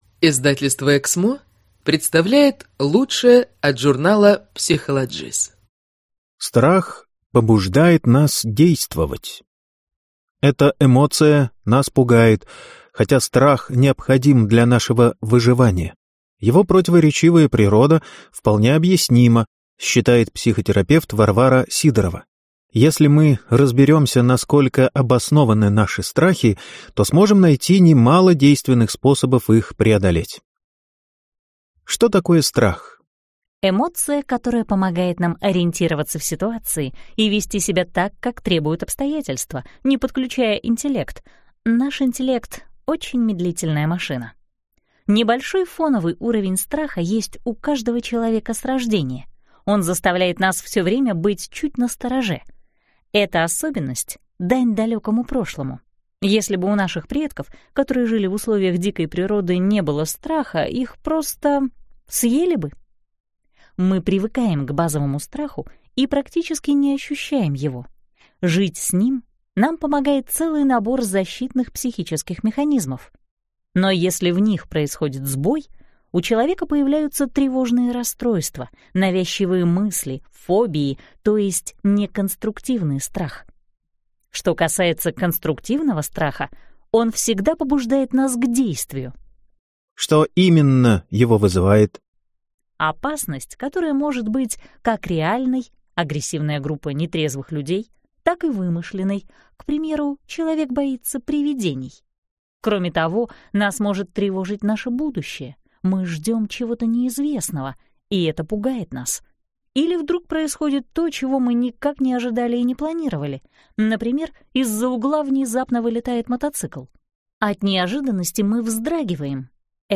Аудиокнига Чего вы боитесь? Как преодолевать страхи | Библиотека аудиокниг